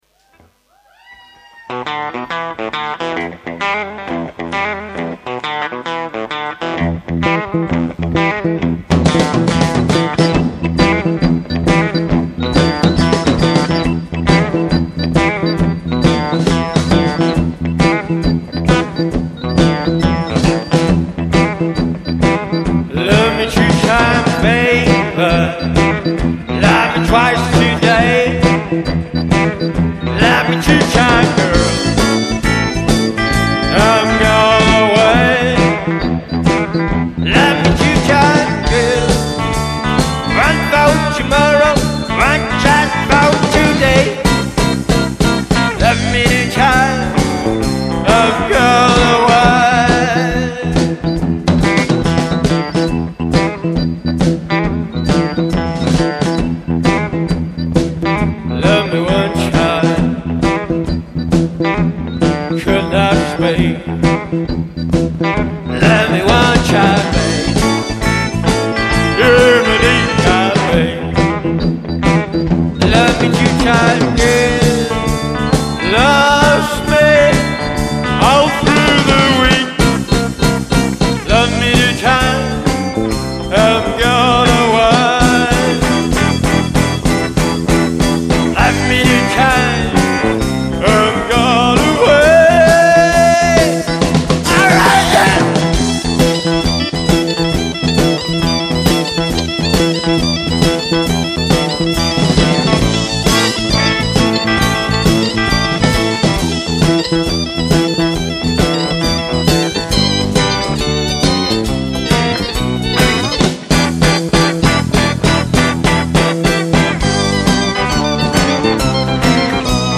Older mp3s with bass guitar player